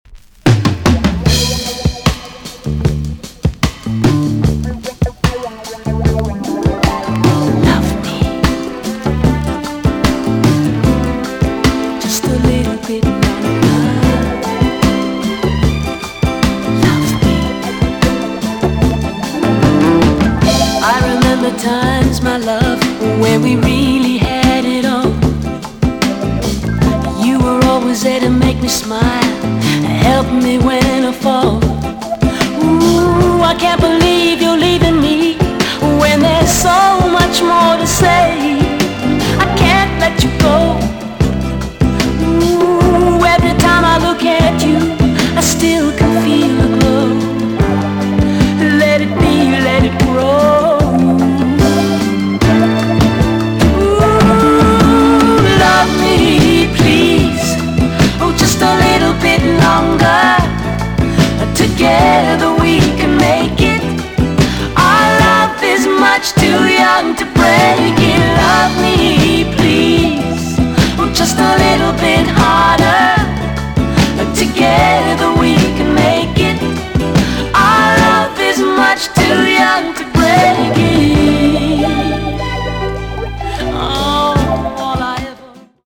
EX- 音はキレイです。
1976 , WICKED JAMAICAN SOUL TUNE!!